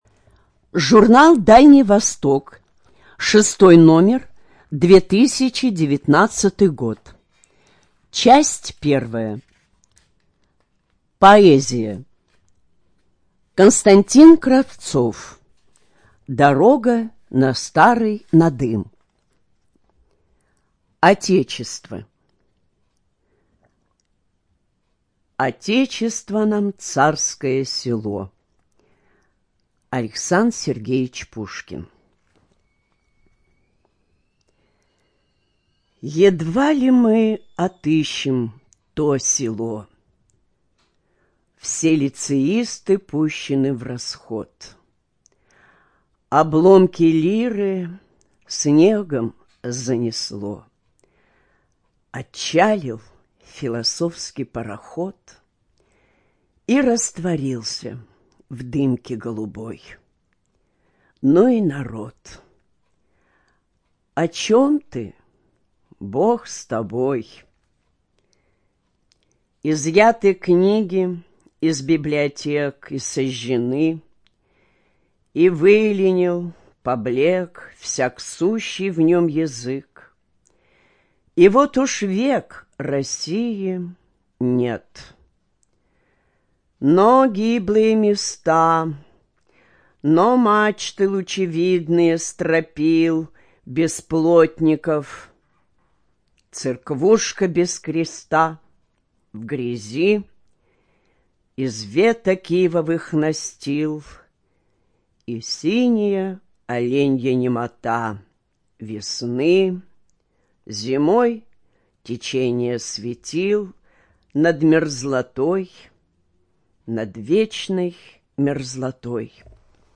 Студия звукозаписиХабаровская краевая библиотека для слепых